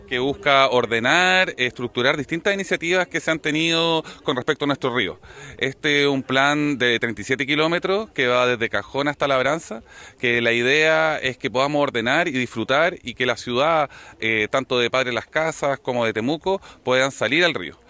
El seremi de Vivienda y Urbanismo en La Araucanía, Patricio Escobar, detalló los alcances del citado Plan que sigue avanzando en sus etapas previas, antes de elaborarlo como tal y así, con proyectos incluidos, presentarlo a financiamiento.